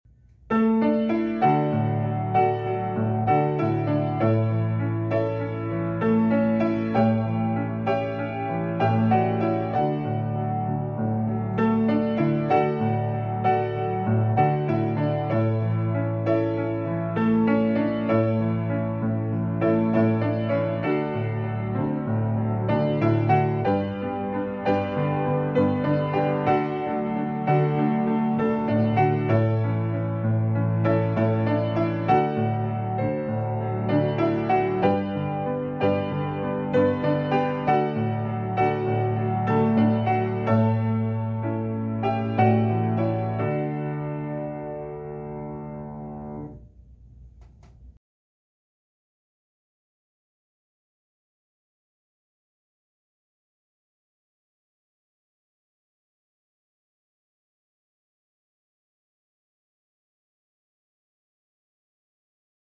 piano hymn